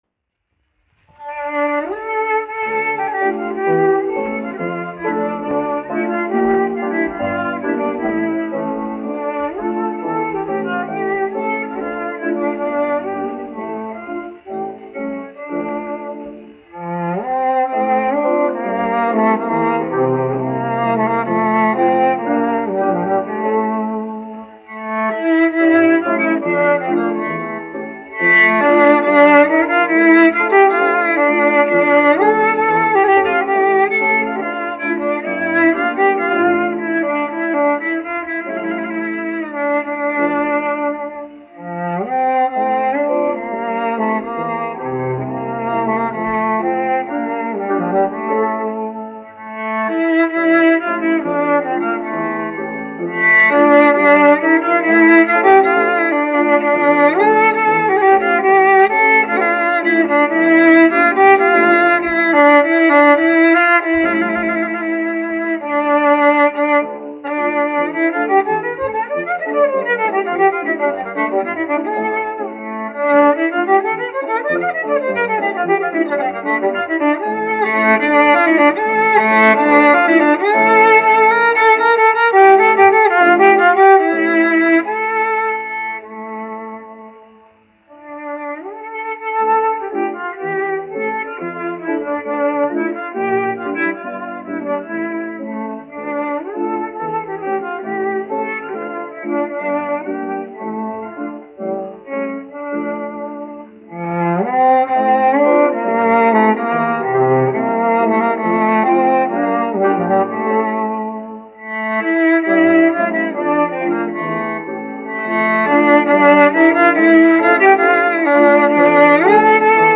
1 skpl. : analogs, 78 apgr/min, mono ; 25 cm
Čella un klavieru mūzika, aranžējumi
Skaņuplate
Latvijas vēsturiskie šellaka skaņuplašu ieraksti (Kolekcija)